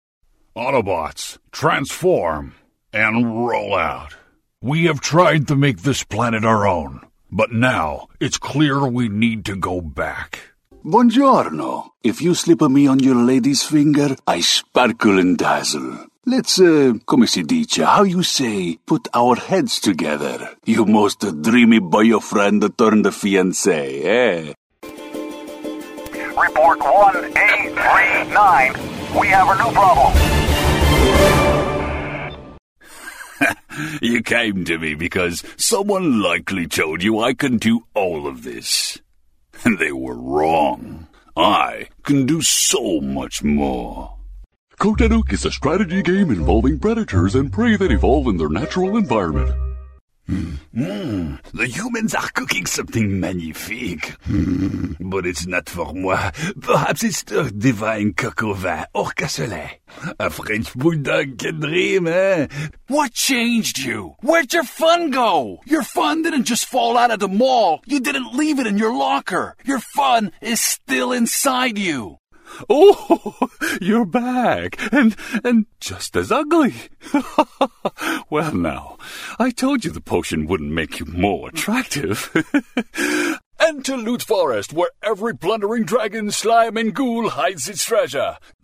Character voices, games and ads